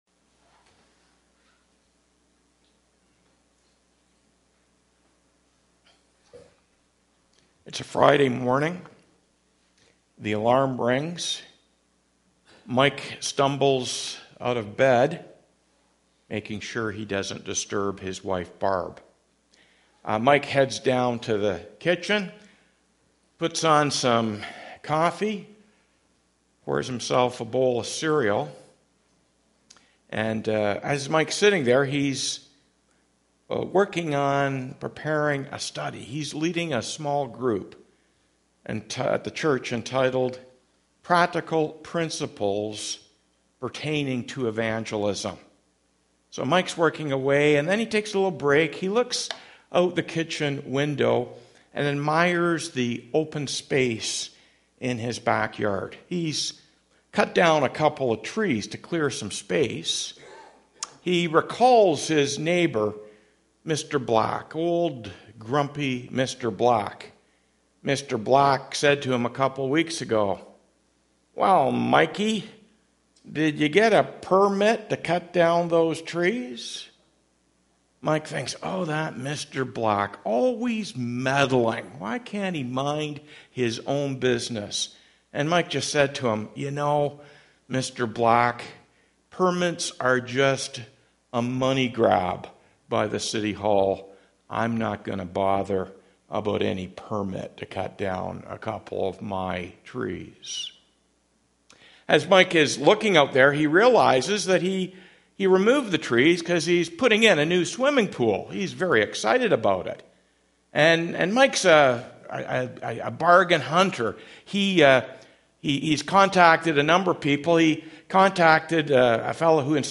Weekly Sermons Grace Driven Living in Difficult Times
18 Please excuse a minor audio variances, due to re-recording issues.